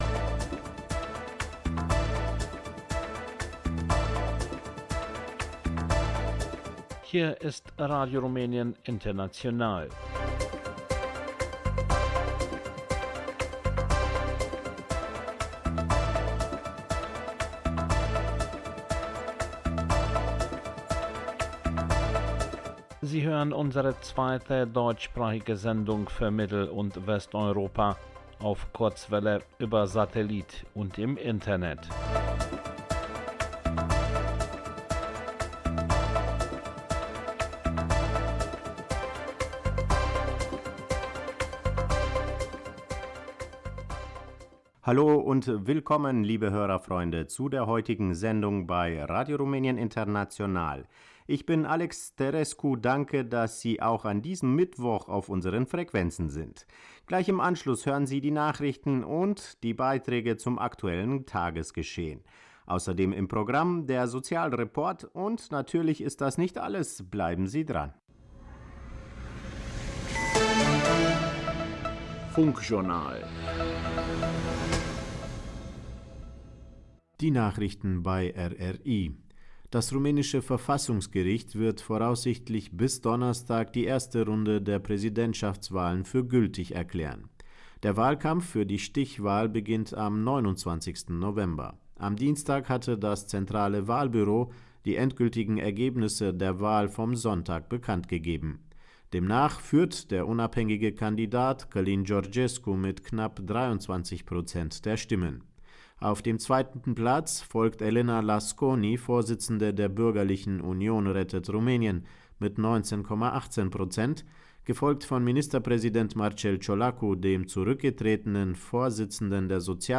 Funkjournal, Sozialreport, Volksmusik, Blickpunkt Kultur